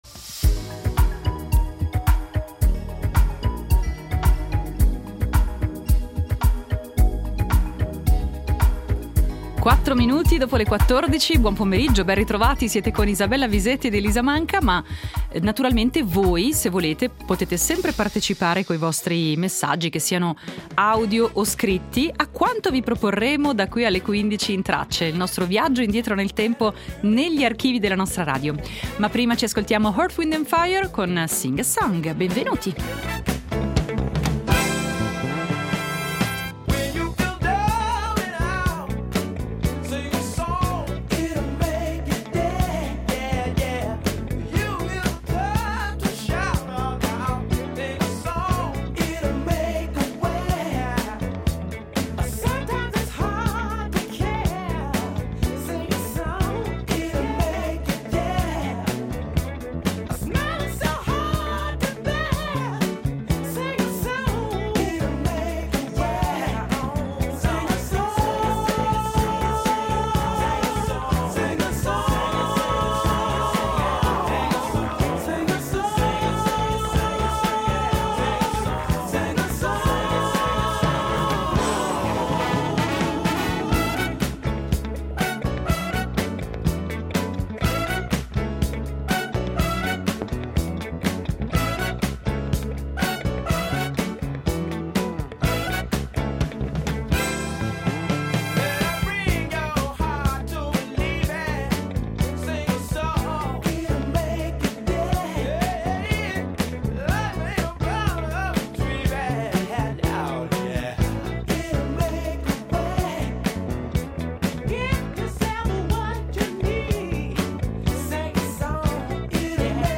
In Tracce , estratti d’archivio su Claude Monet, pittore francese nato oggi nel 1840, sui cannoni da neve, sull’invenzione del cellophane e sullo sciopero generale del 1918.